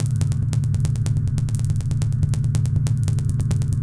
Abstract Rhythm 38.wav